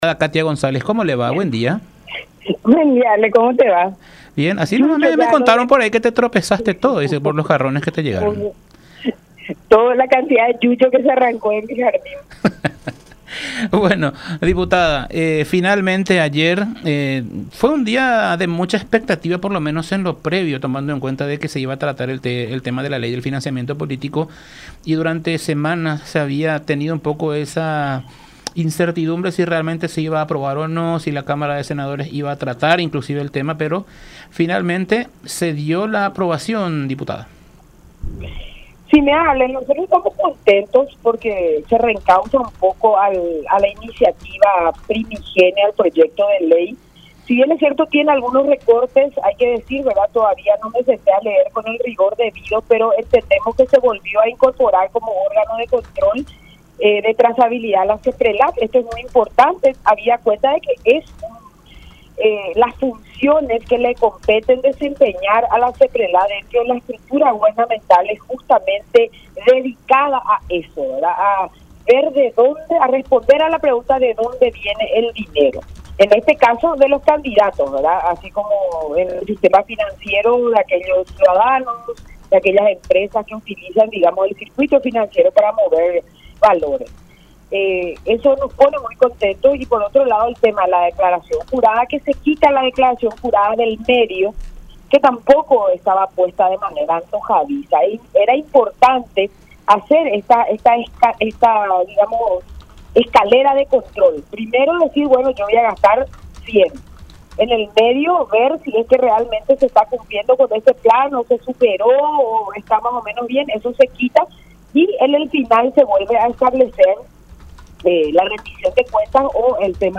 “Se reencausa la iniciativa del proyecto de ley”, aseveró González, del Partido Encuentro Nacional (PEN), en contacto con La Unión, destacando principalmente que se le haya devuelto poder a la Secretaría de Prevención de Lavado de Dinero (SEPRELAD) para intervenir automáticamente en caso de detectar irregularidades en las rendiciones de cuentas de los precandidatos y candidatos.